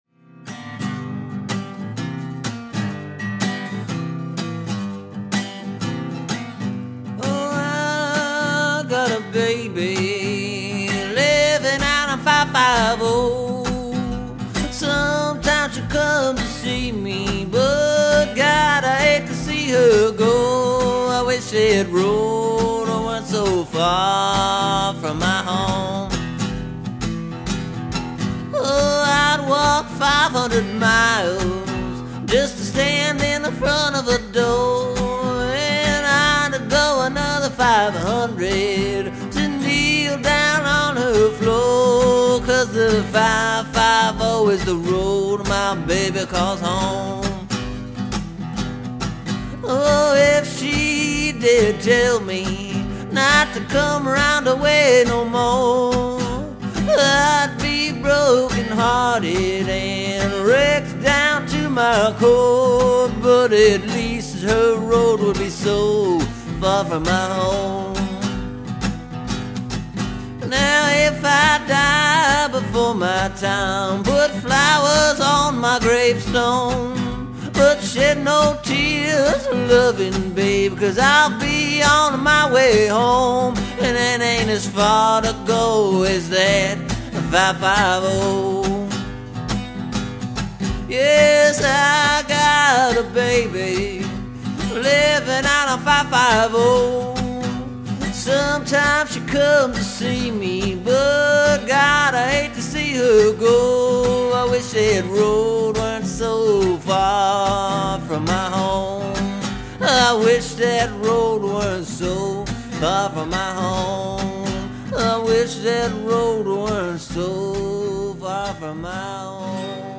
It’s a fairly straight forward tune, I think.
The notes are all sung and played.